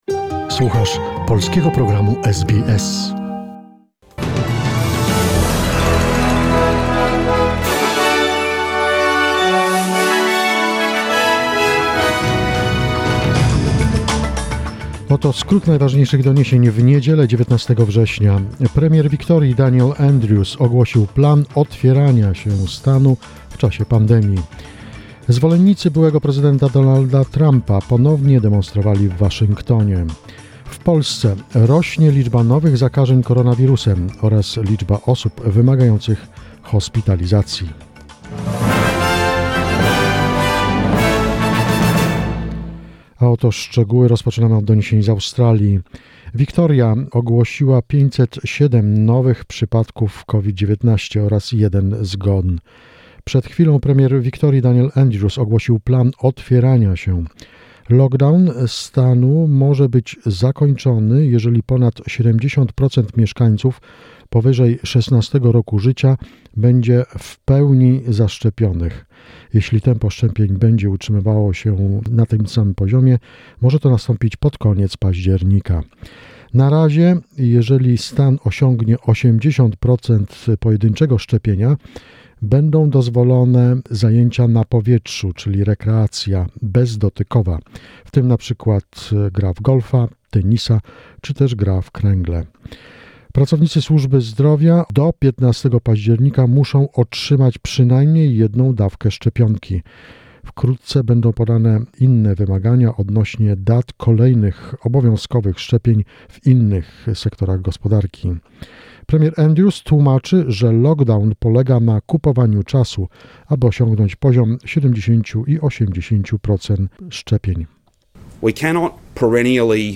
SBS News in Polish, 19 September 2021